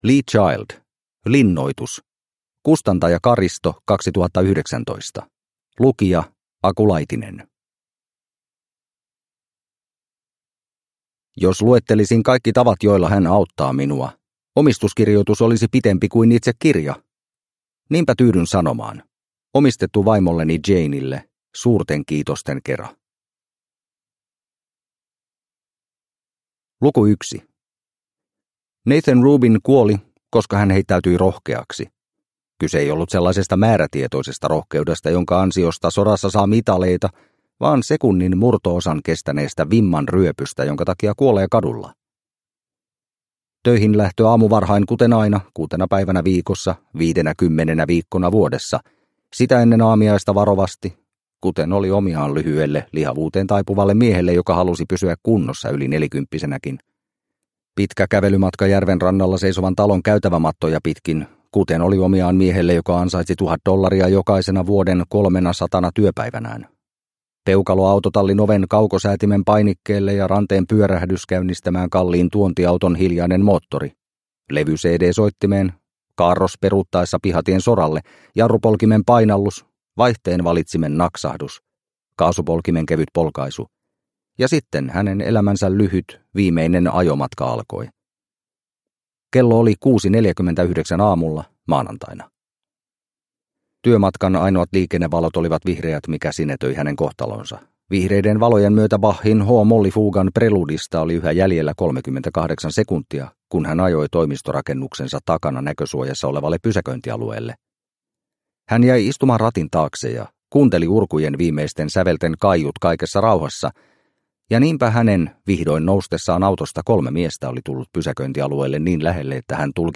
Linnoitus – Ljudbok – Laddas ner